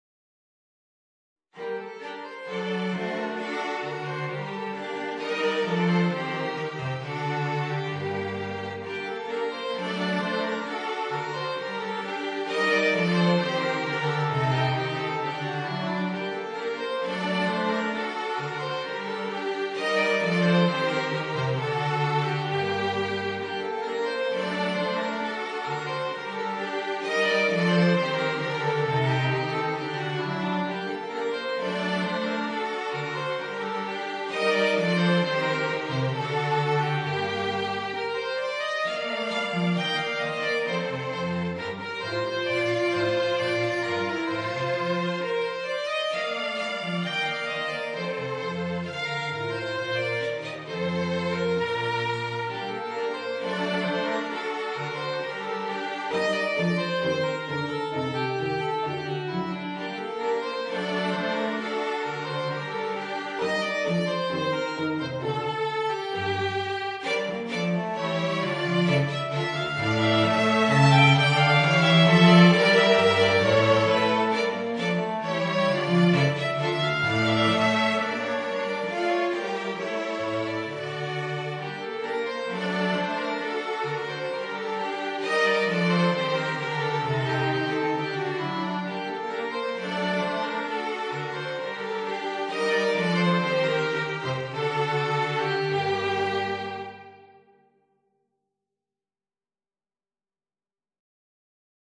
Voicing: Trombone and String Quartet